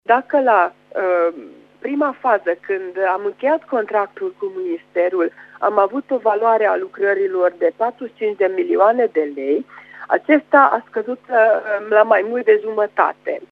Revine viceprimarul municipiului, Sztakics Éva: